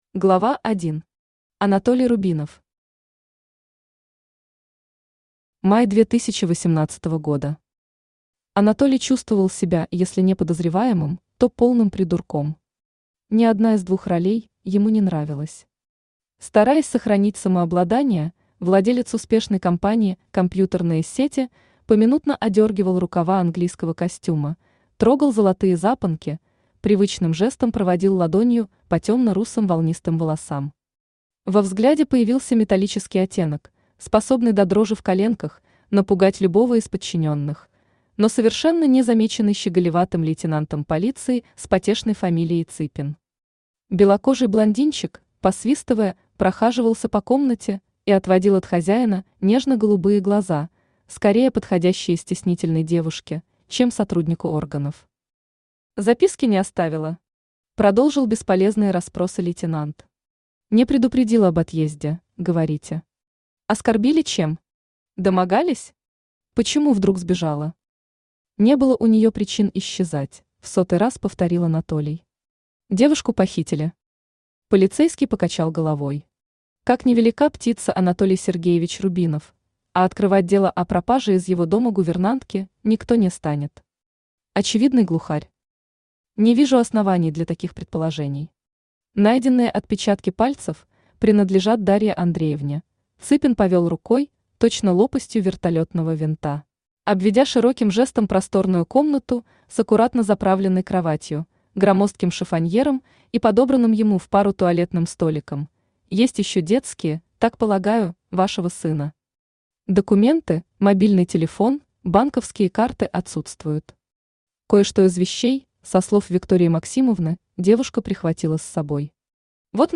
Аудиокнига Простить нельзя расстаться | Библиотека аудиокниг
Aудиокнига Простить нельзя расстаться Автор Ирина Ваганова Читает аудиокнигу Авточтец ЛитРес.